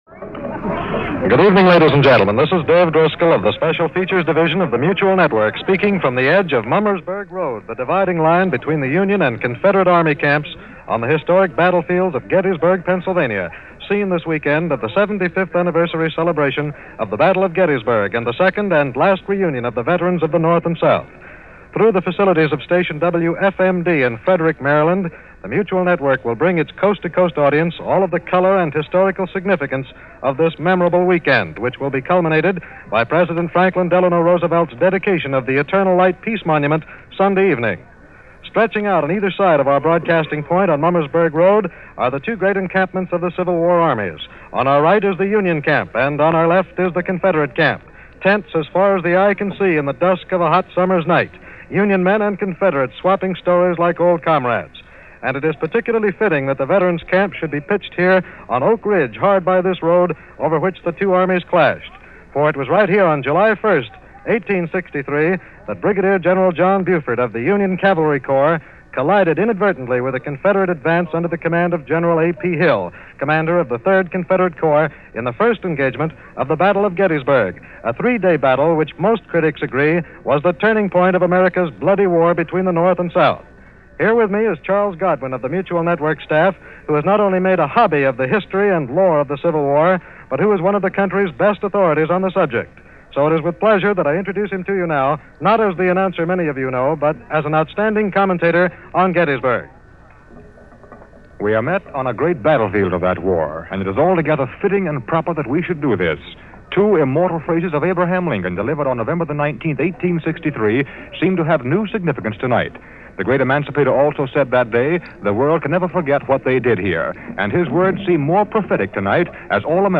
July 1st of this year marked the 162nd anniversary of the Battle of Gettysburg , the battle that signaled a turn in the Civil War. Obviously, no recordings or on-the-spot news reports from that period exist, but a 75th anniversary observance of the battle in 1938 yielded interviews with some of the last survivors of that battle, in what was referred to as the last reunion of surviving participants in the Civil War.